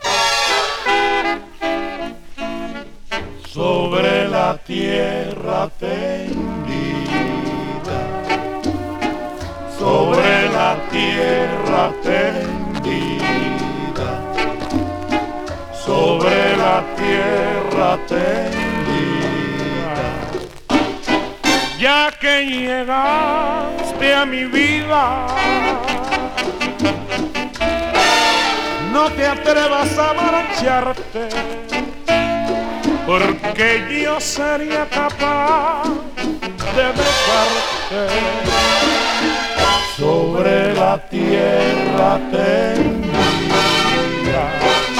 World, Latin, Son　Venezuela　12inchレコード　33rpm　Mono